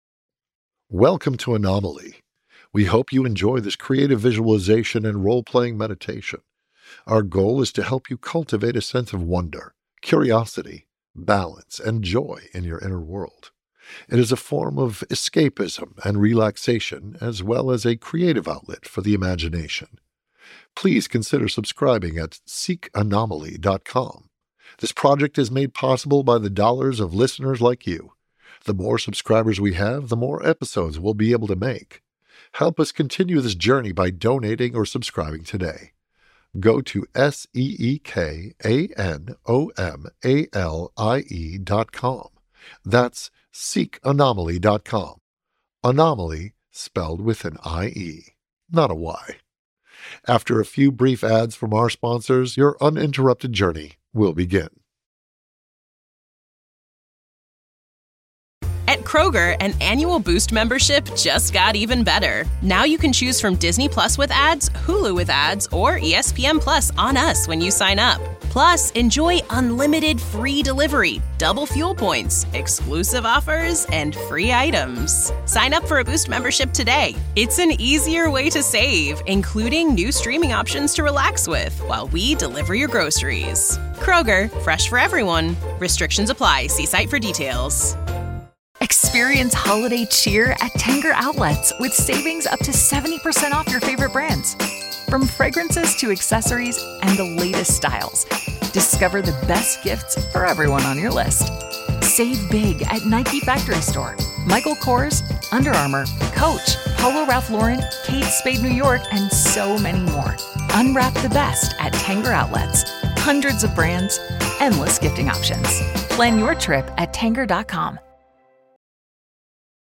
Anomalie (role-playing visualization)
Featured Voice: Todd Stashwick